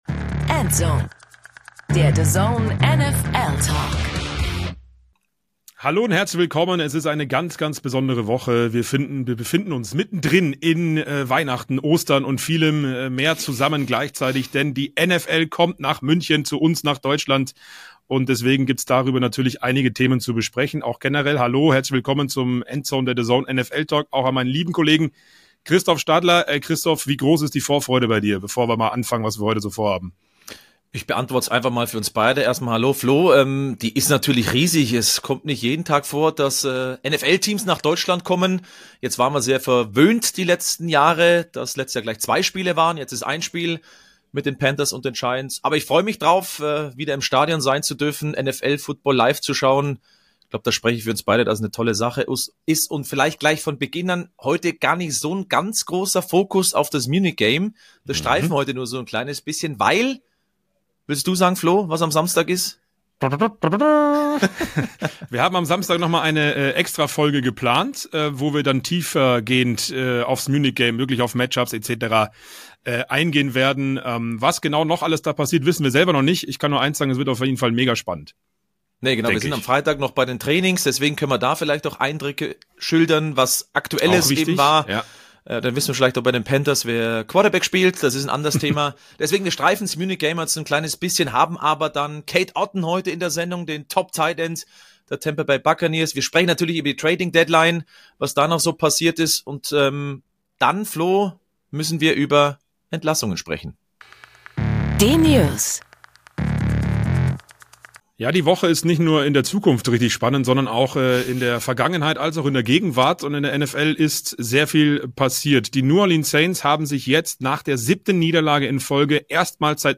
Der Tight End der Tampa Bay Buccaneers stand uns ausführlich Rede und Antwort.